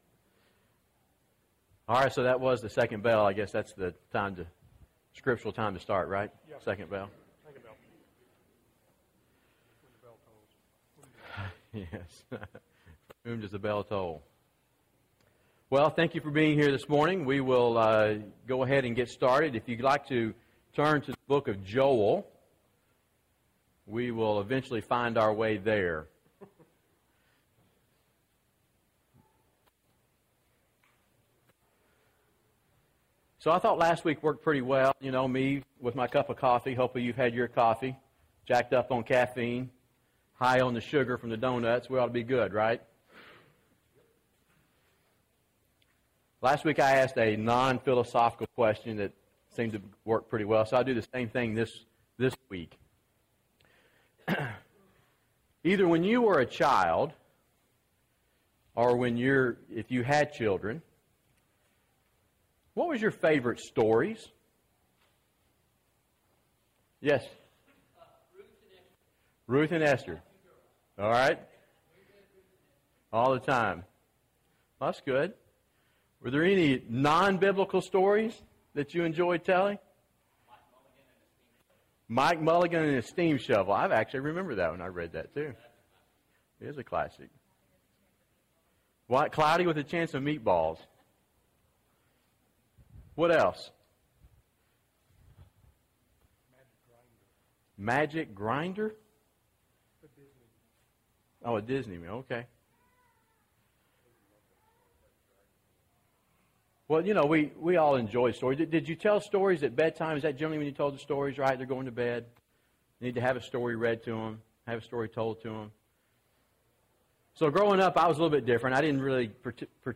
What Should We Teach Our Children? (6 of 12) – Bible Lesson Recording